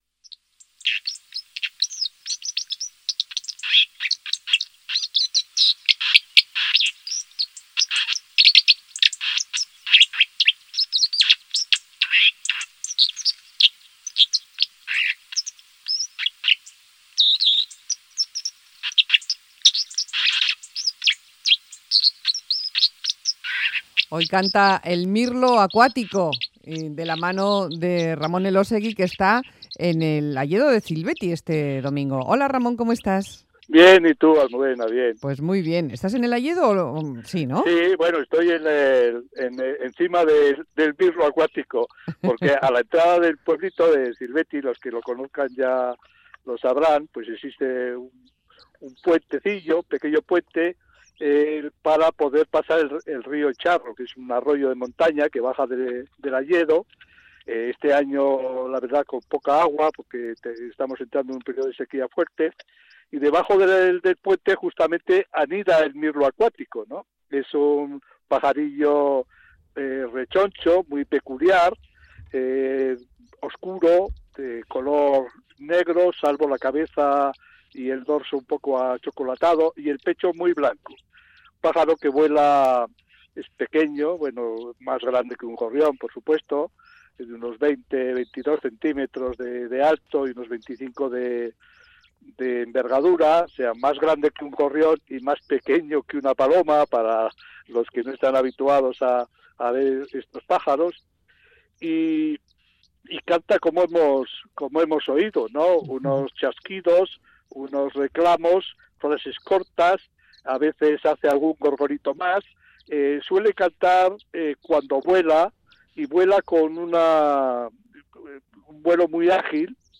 El mirlo acuático